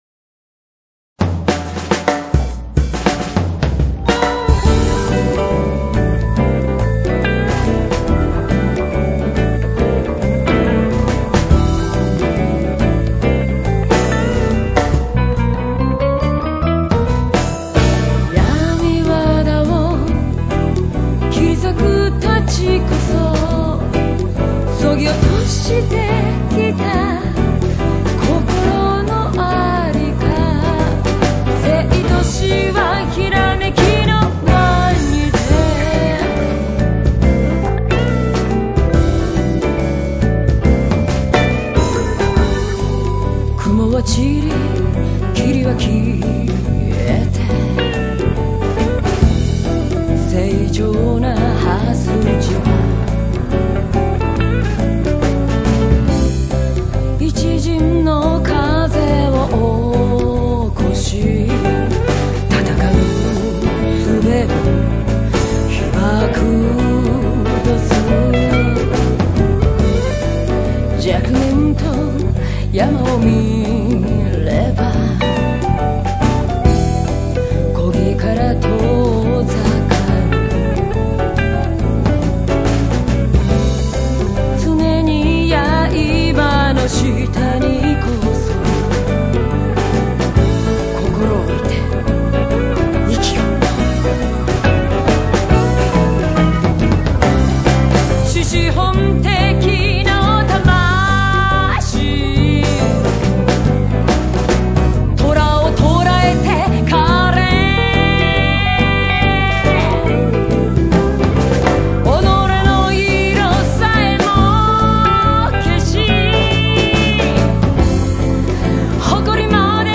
It's JAZZ!